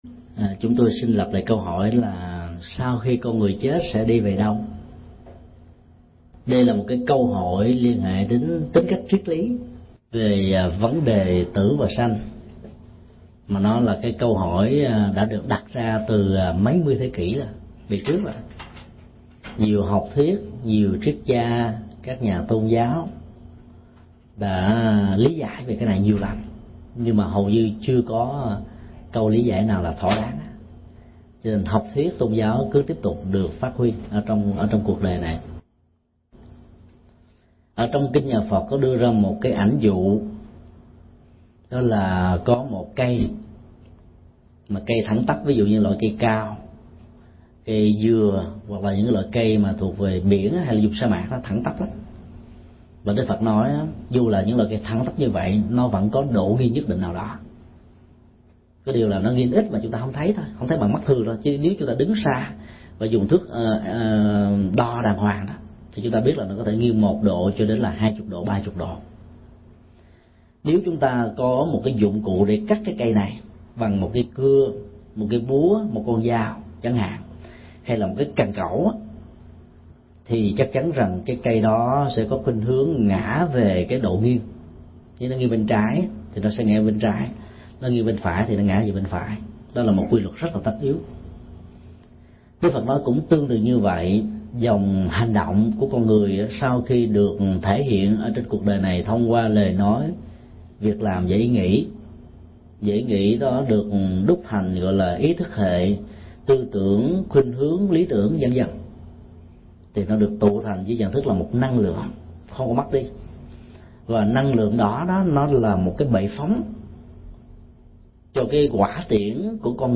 Vấn đáp: Sau khi chết con người sẽ đi về đâu – thầy Thích Nhật Từ